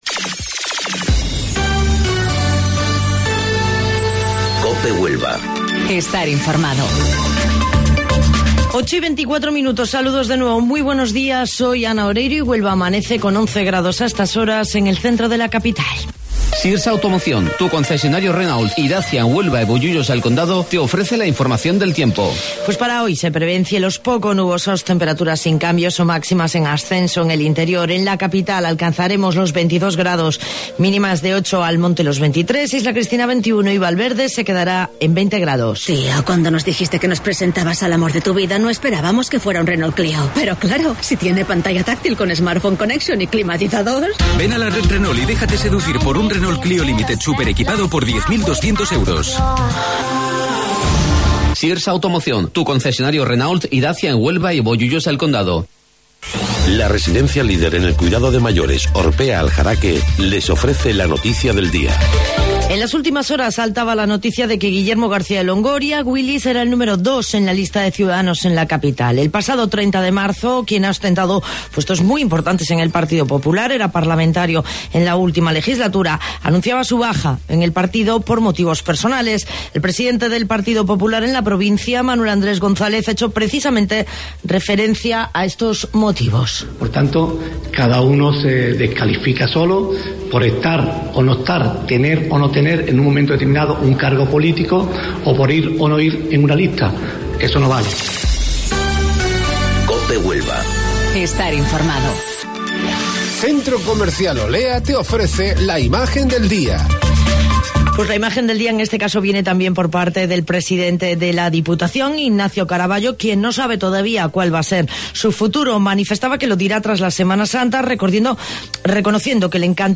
AUDIO: Informativo Local 08:25 del 11 de Abril